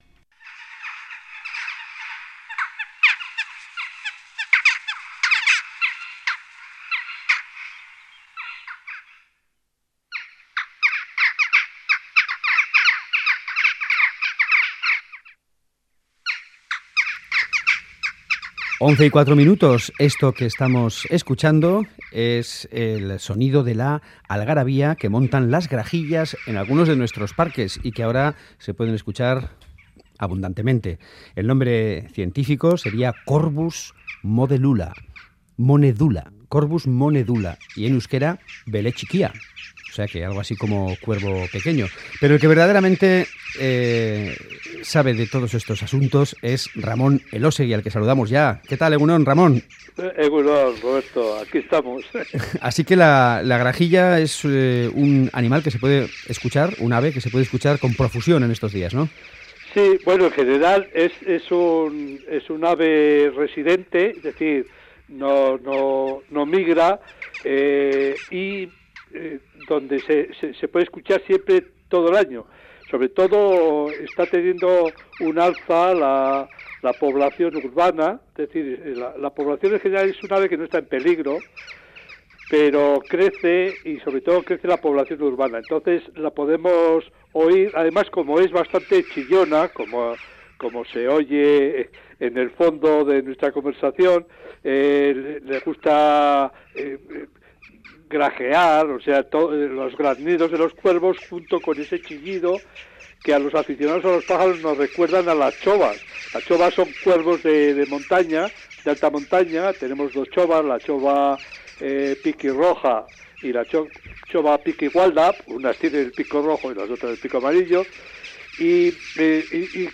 La grajilla occidental